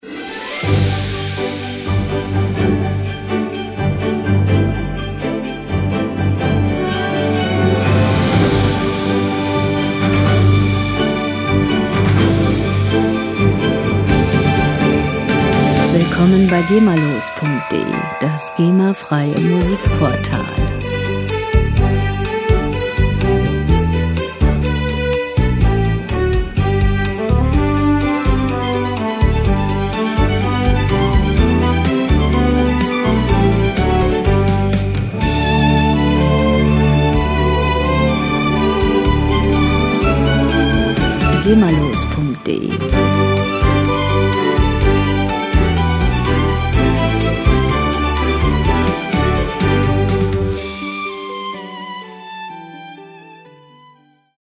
• Weihnachtslied aus Wales
startet mit einem lebendigen, aufmerksamkeitsstarken Intro